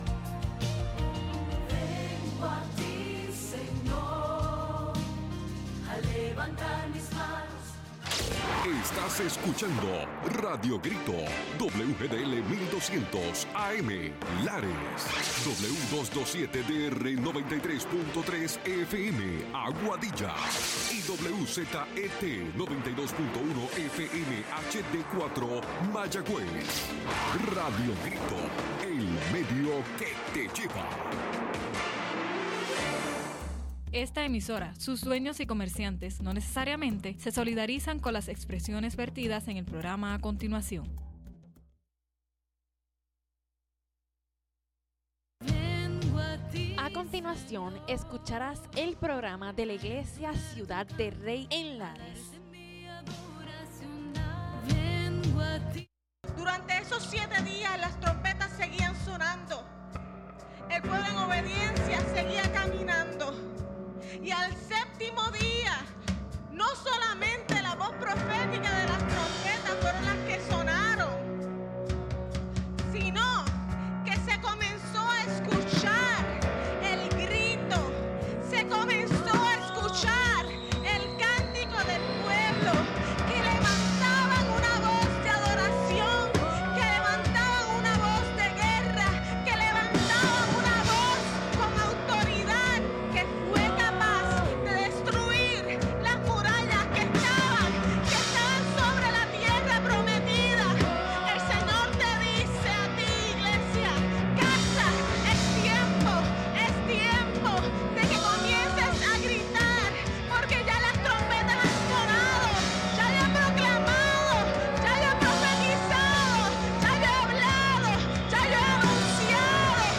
Los hermanos de Ciudad del Rey nos traen un programa especial de su servicio en la iglesia.